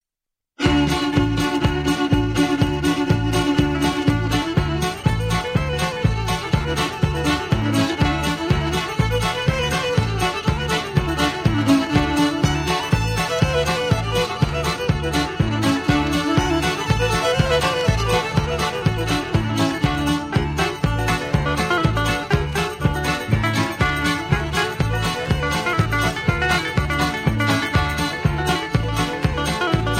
Patter (two instrumentals)